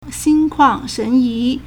心旷神怡 (心曠神怡) xīn kuàng shén yí
xin1kuang4shen2yi2.mp3